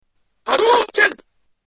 Fireball Sound